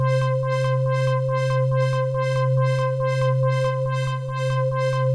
Index of /musicradar/dystopian-drone-samples/Tempo Loops/140bpm
DD_TempoDroneD_140-C.wav